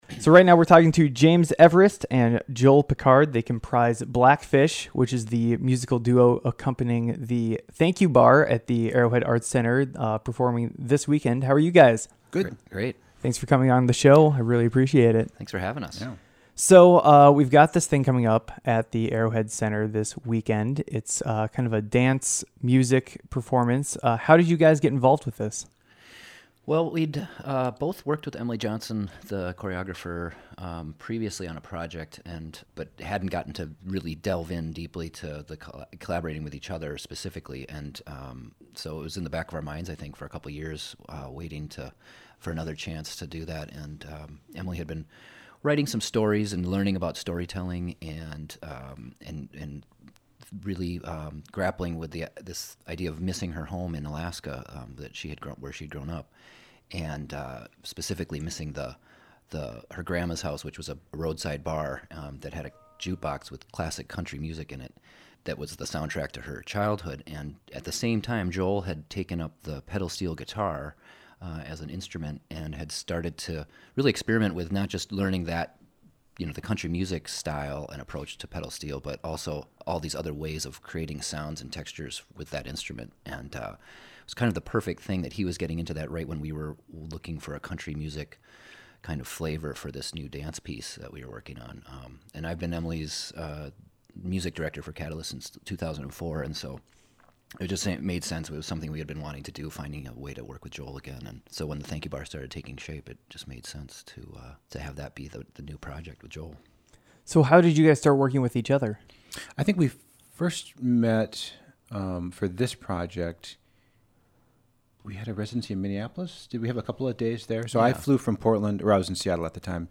We also hear a taste of several of their other musical projects. NOTE: There was a sound error recording this piece. The background static is not caused by your computer's speakers. Program: Scenic Route